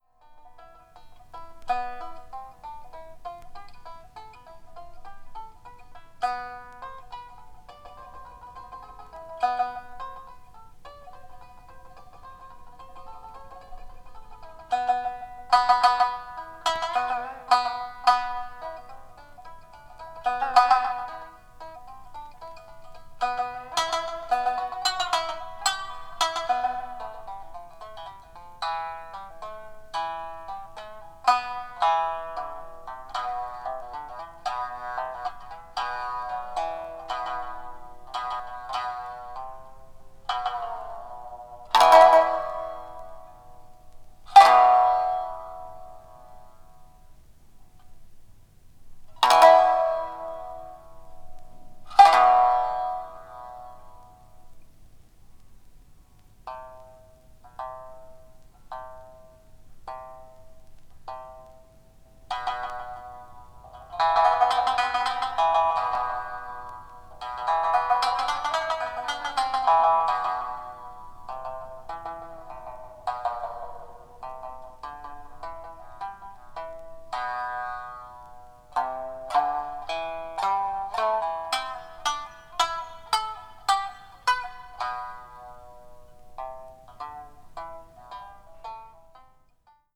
三絃